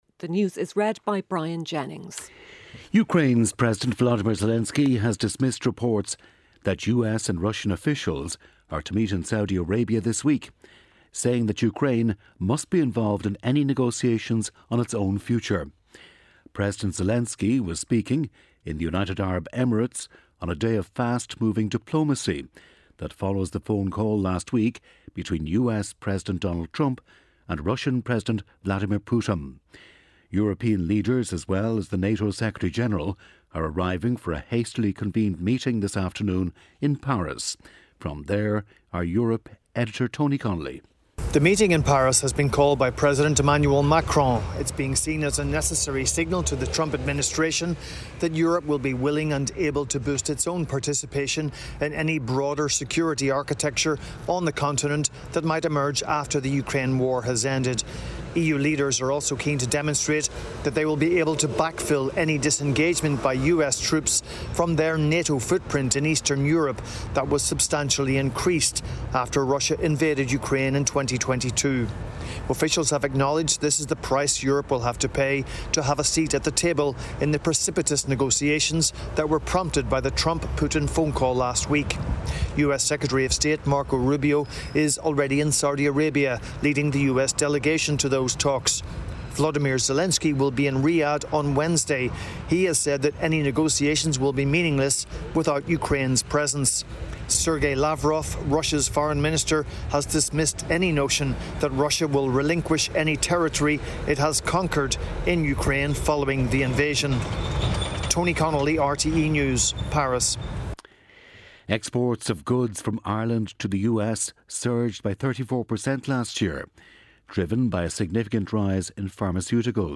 1pm News Bulletin - 17.02.2025